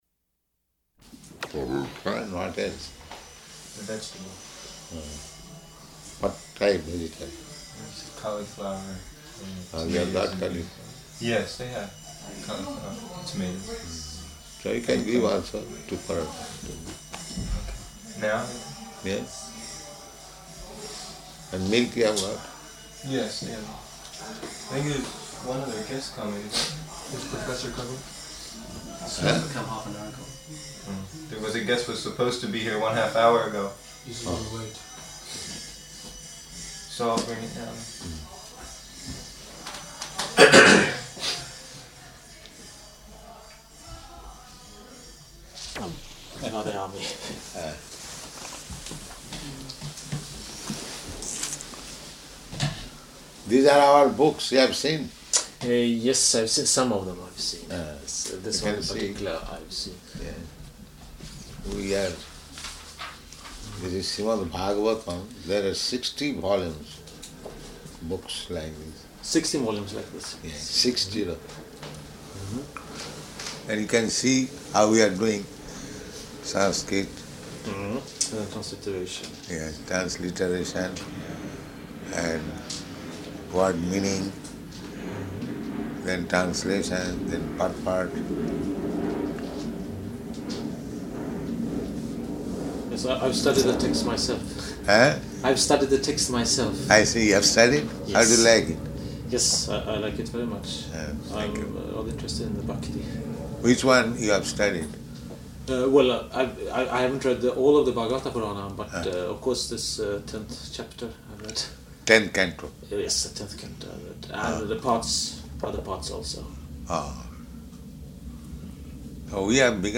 -- Type: Conversation Dated: September 5th 1973 Location: Stockholm Audio file